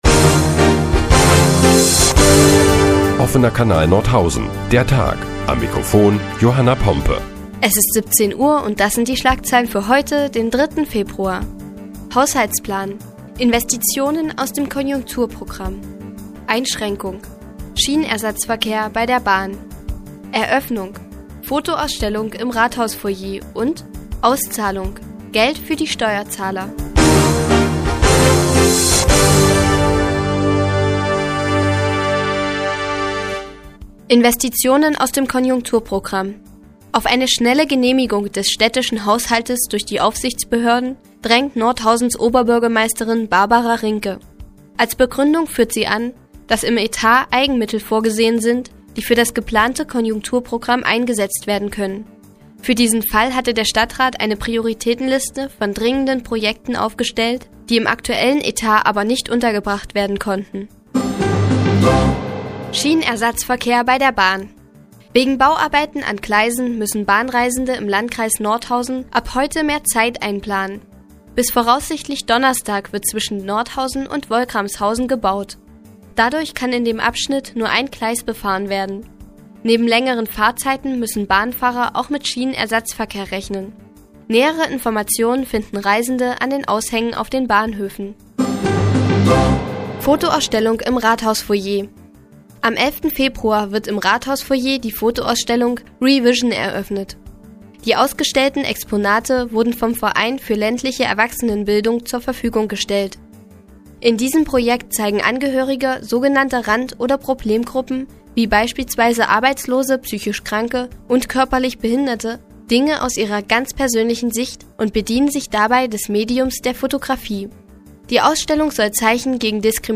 Die tägliche Nachrichtensendung des OKN ist nun auch in der nnz zu hören. Heute geht es unter anderem um den Haushaltsplan für Nordhausen und eine neue Fotoausstellung im Rathaus-Foyer.